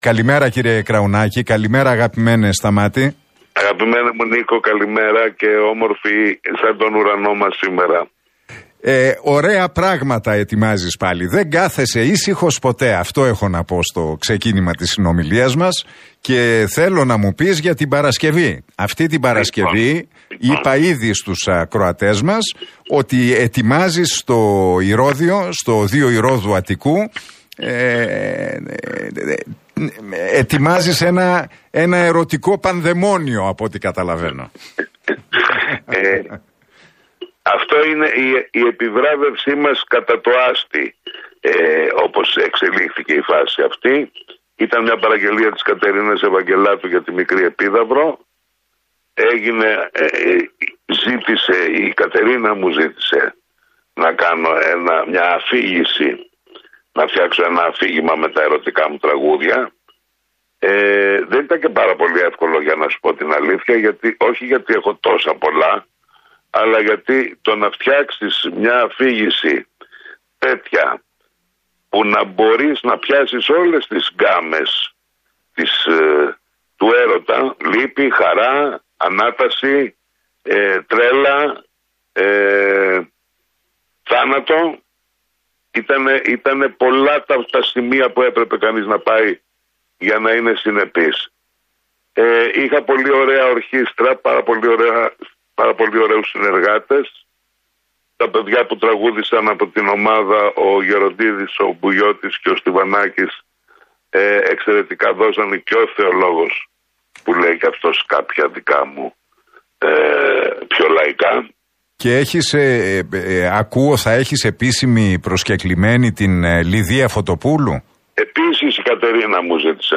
Ο Σταμάτης Κραουνάκης μίλησε στην εκπομπή του Νίκου Χατζηνικολάου στον Realfm 97,8 για την συναυλία του στο Ηρώδειο, την Παρασκευή 13 Οκτωβρίου.